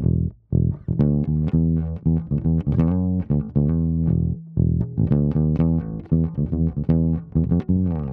12 Bass PT4.wav